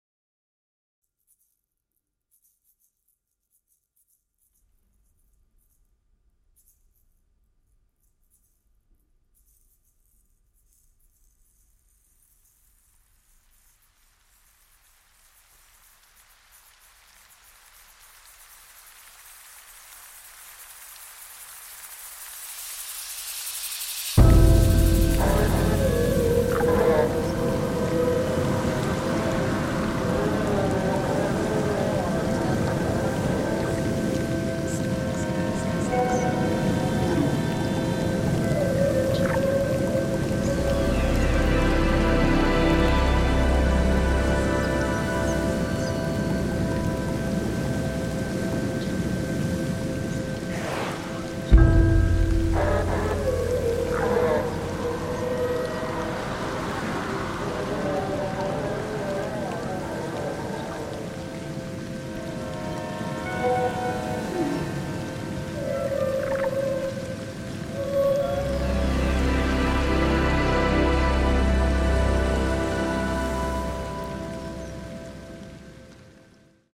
for sampler and ensemble